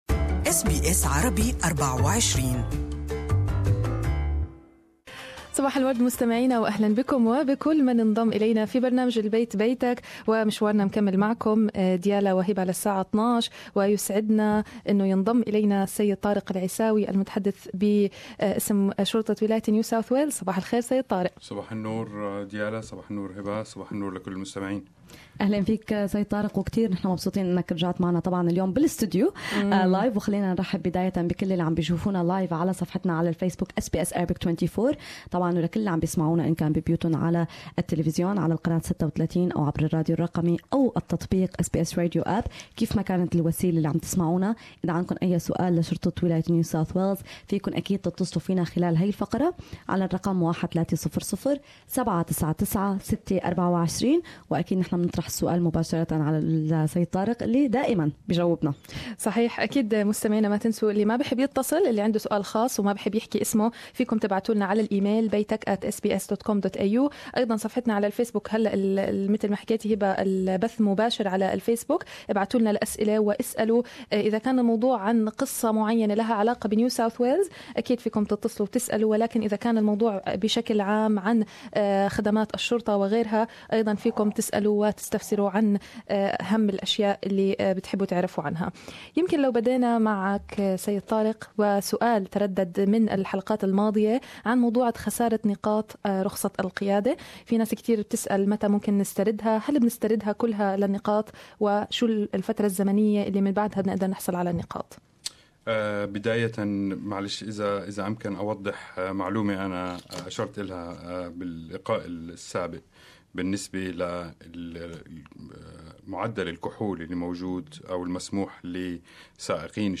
ضمن اللقاء الاسبوعي مع الشرطة ضمن برنامج البيت بيتك